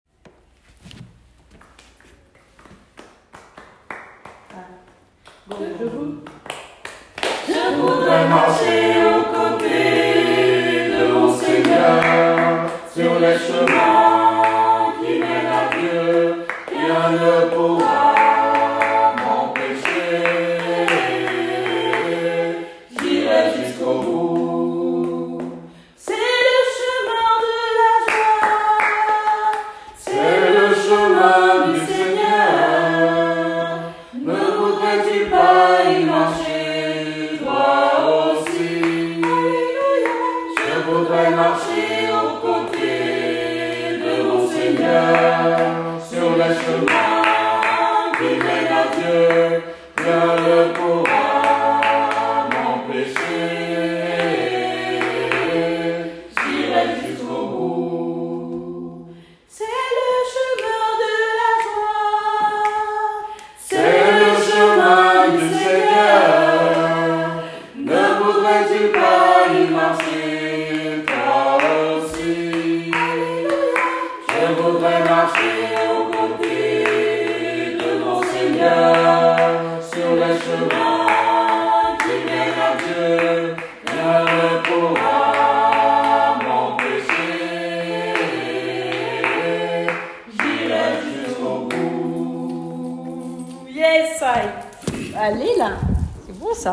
Une chorale ouverte à tous et toutes sans exception.
Quelques extraits sonores de la chorale